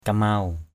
/ka-maʊ/ (d.) sẩy lửa = prurit.